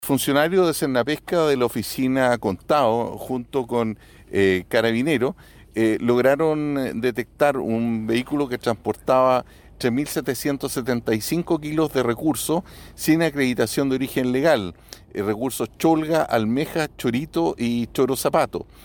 Así lo detalló el director regional de Sernapesca, Eduardo Aguilera, quien señaló que en el móvil había más de 3 mil kilos de los recursos.